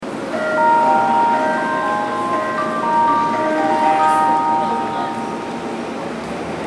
みなとみらい駅　Minatomirai Station ◆スピーカー：National天井型
1番線発車メロディー